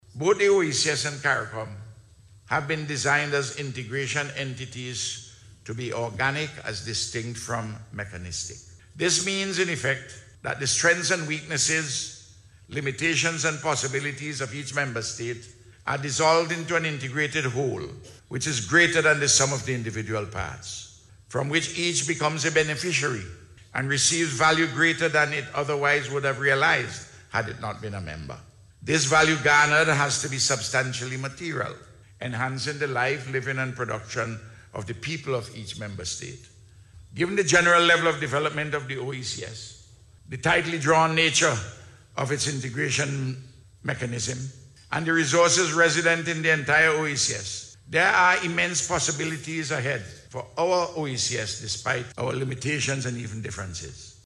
The point was made by Prime Minister, Dr. Ralph Gonsalves while delivering remarks at the opening of the 77th Meeting of the OECS Authority held on Wednesday.